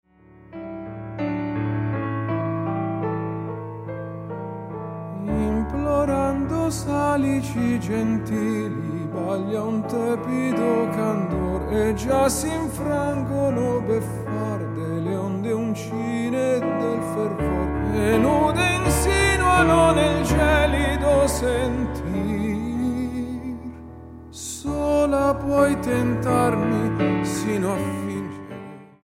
STYLE: Classical
Piano